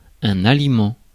Ääntäminen
IPA: /a.li.mɑ̃/